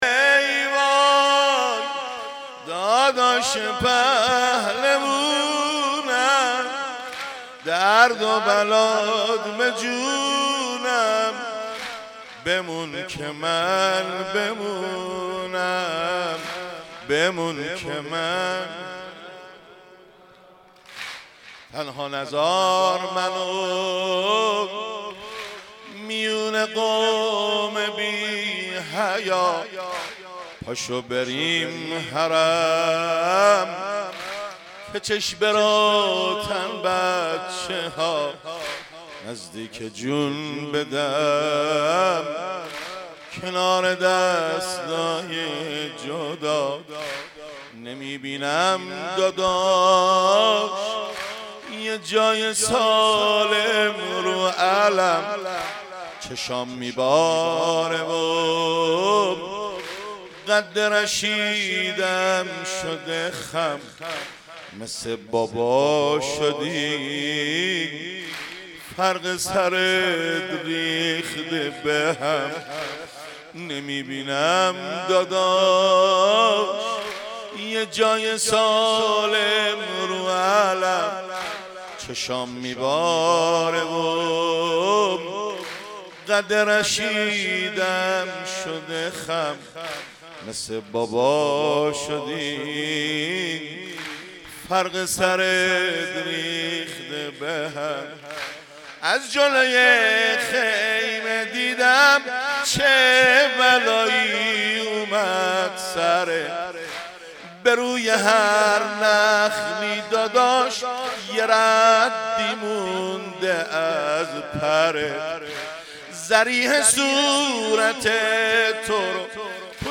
مناسبت : دهه دوم محرم
قالب : روضه